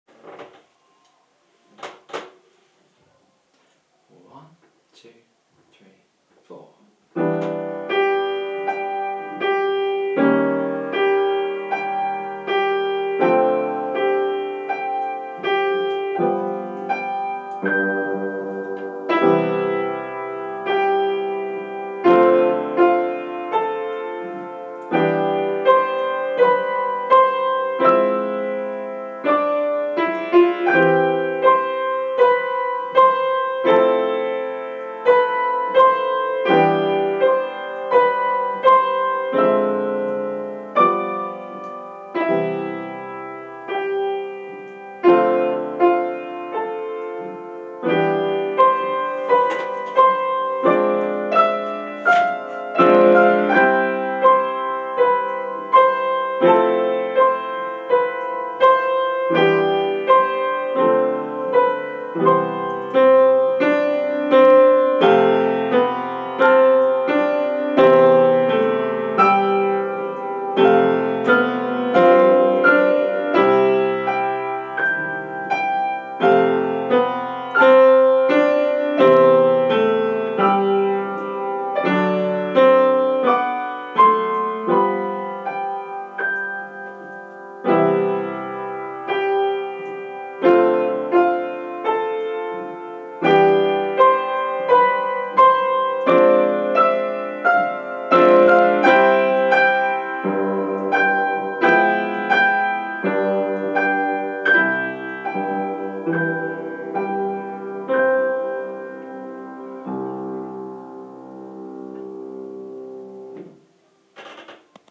Duet 12/02/2016